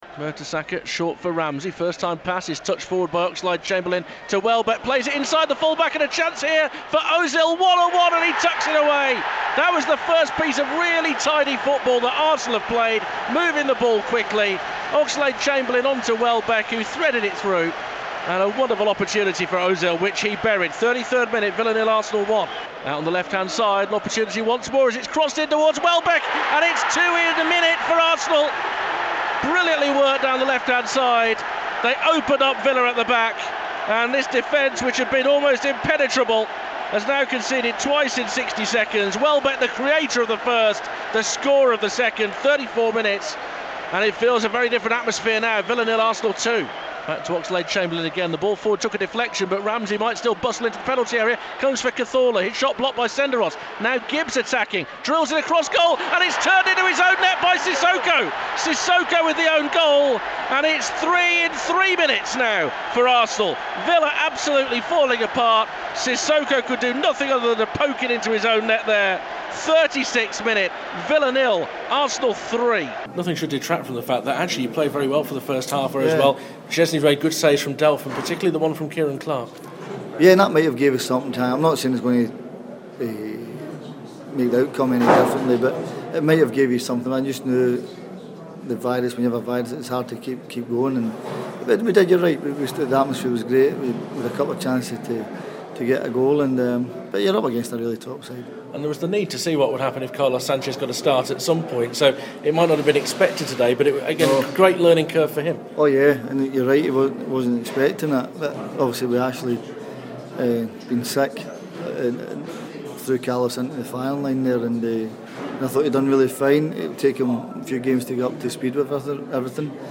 talks to Paul Lambert at Villa Park